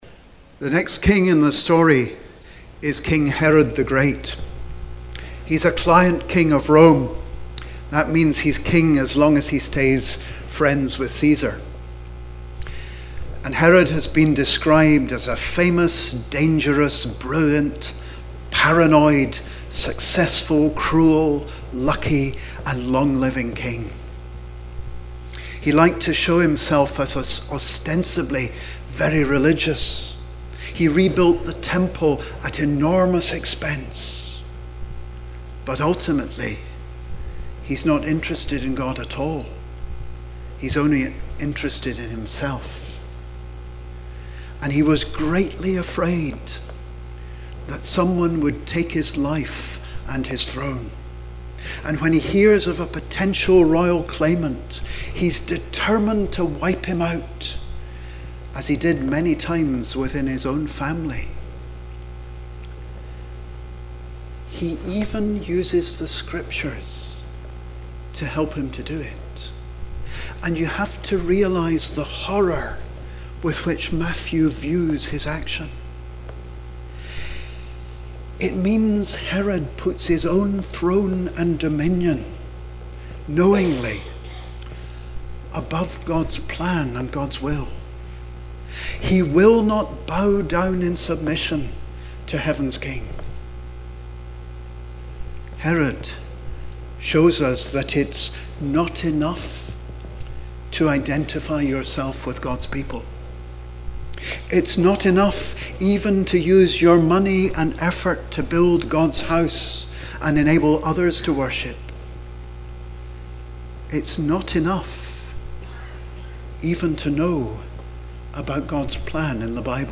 Christmas Eve at St. Mungo's - 24 December 2018
Watchnight Service and the celebration of Jesus birth